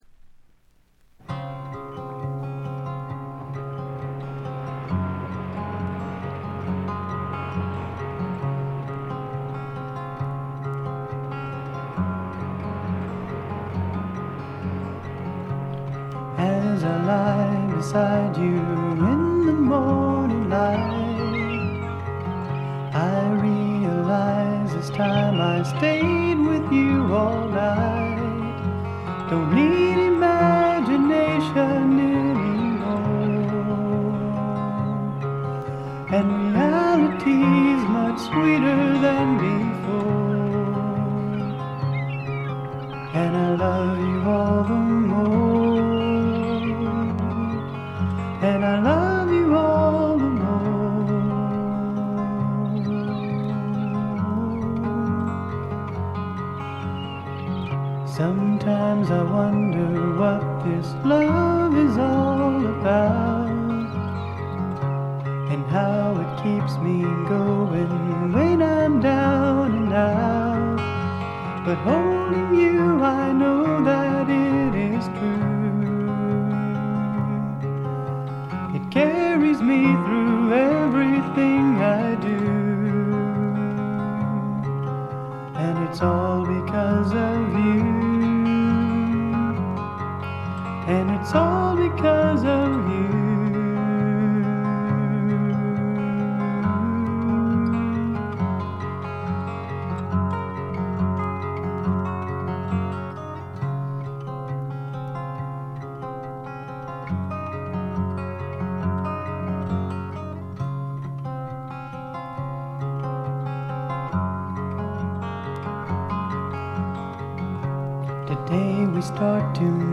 軽いバックグラウンドノイズ。
ローナーフォーク、ドリーミーフォークの逸品です。
試聴曲は現品からの取り込み音源です。